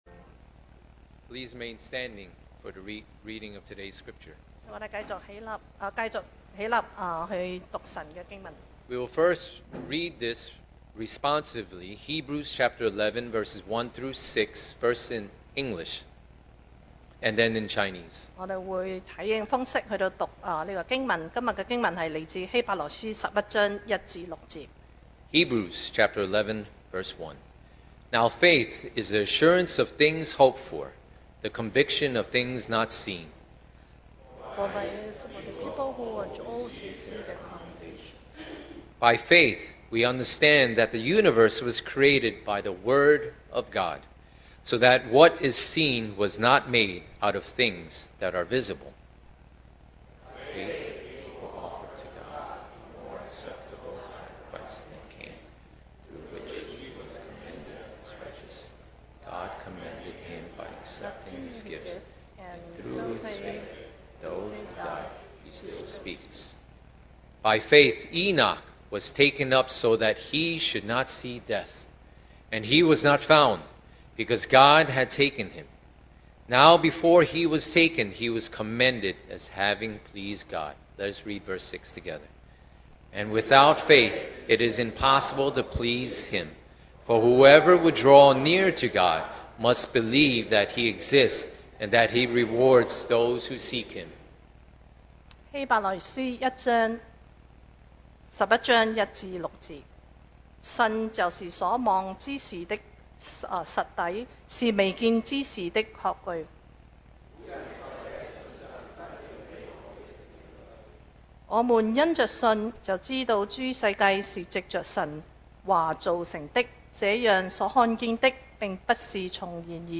Hebrews 11:1-6 Service Type: Sunday Morning What Do You Really Believe?